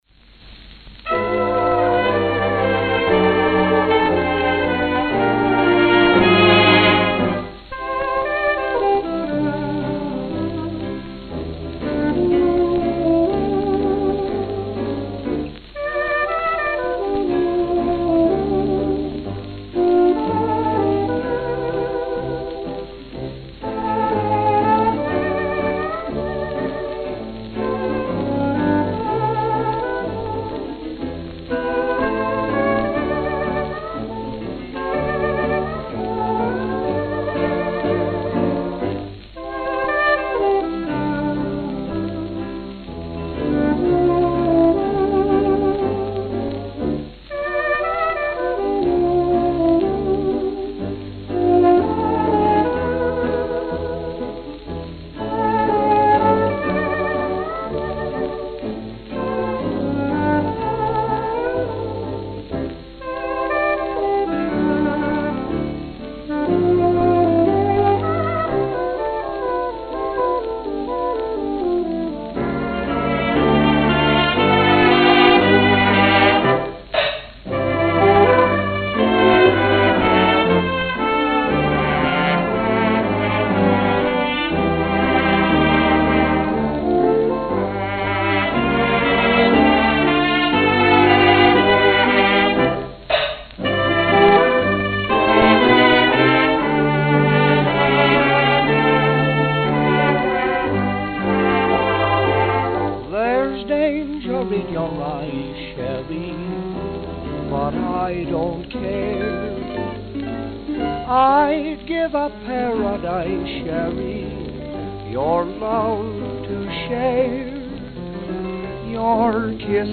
New York, New York New York, New York
Note: Studio sounds before and after.